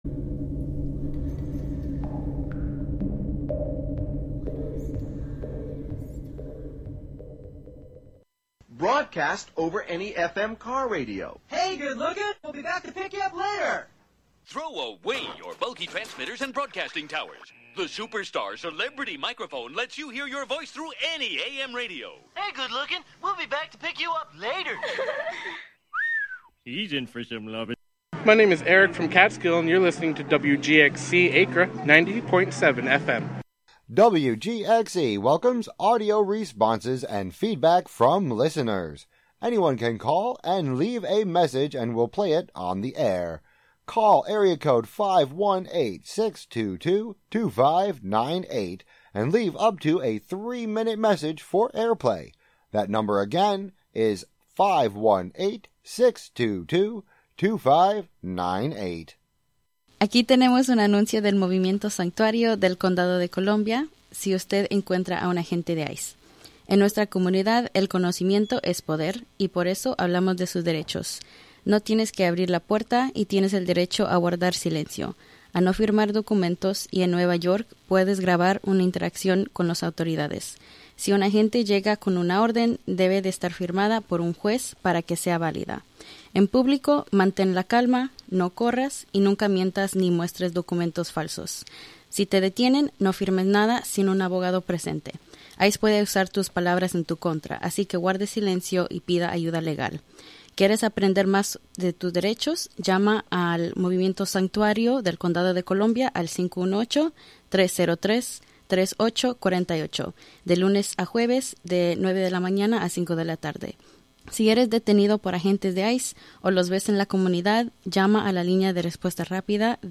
Through music, song, and story, embodied wisdom is shared and activated to maintain the fluidity of a functional, balanced, and regenerative society.